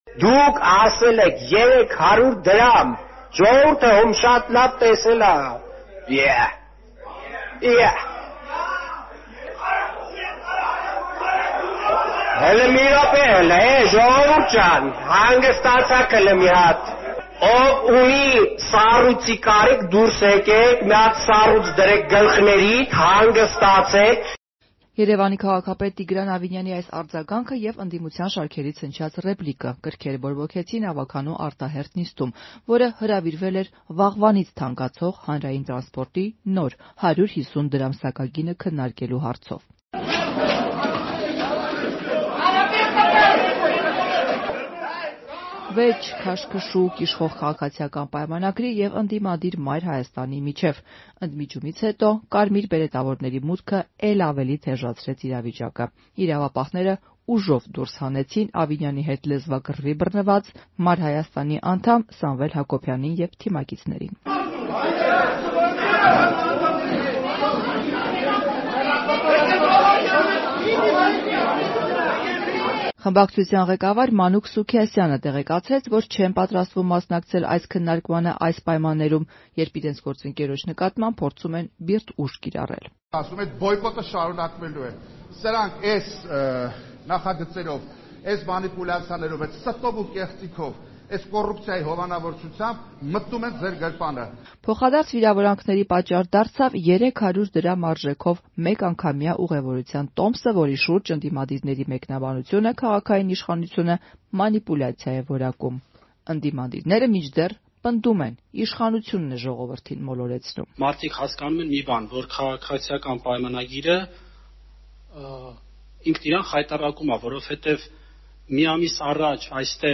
Վեճ, քաշքշուկ՝ ՔՊ-ի և «Մայր Հայաստան»-ի միջև Երևանի ավագանու արտահերթ նիստի ընթացքում
Ռեպորտաժներ